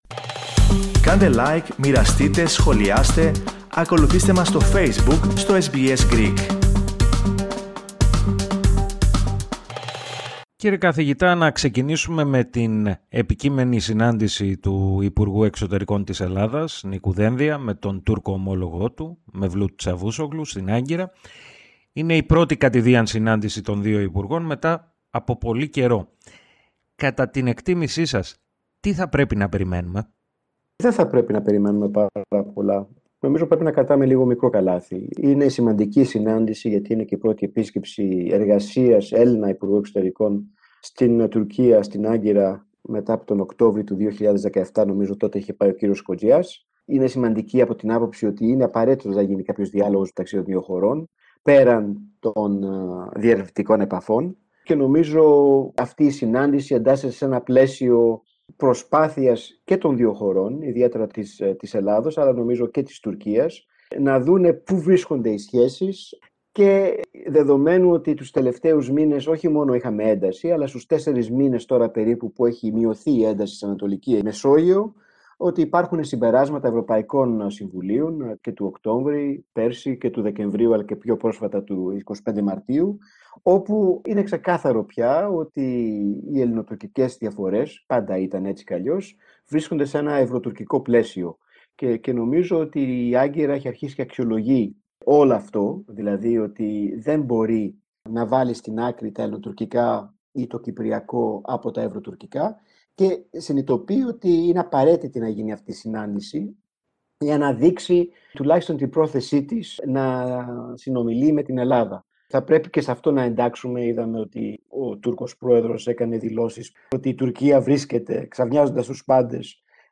Εφ’ όλης της ύλης συνέντευξη